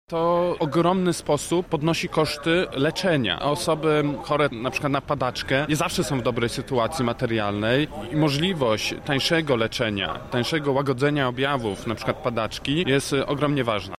Manifestacje odbyły się w sobotę pod lubelskim Ratuszem.